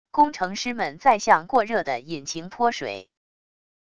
工程师们在向过热的引擎泼水wav音频